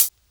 Index of /90_sSampleCDs/Best Service ProSamples vol.15 - Dance Drums [AKAI] 1CD/Partition B/HH 001-052